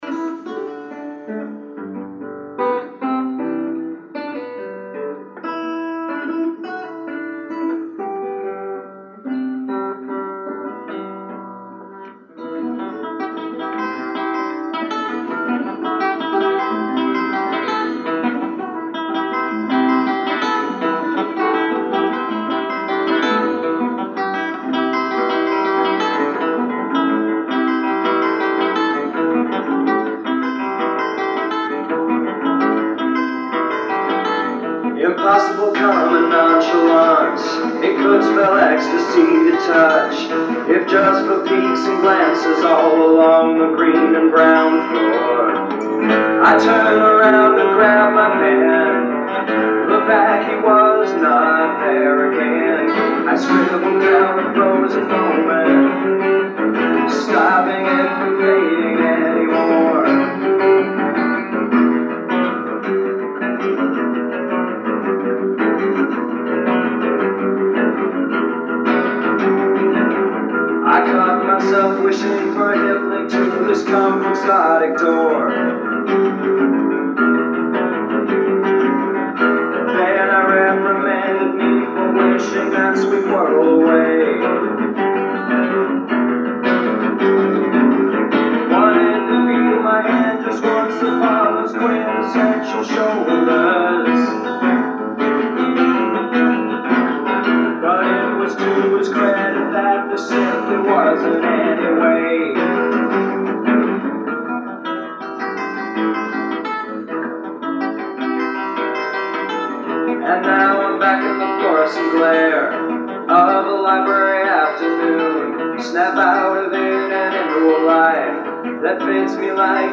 Vocals
= Lead Guitar
backing Guitar